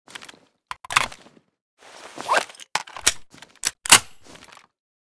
g43_reload_empty.ogg